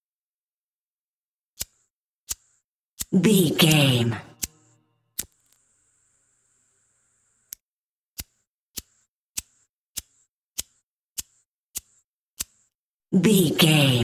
Sound Effects
fire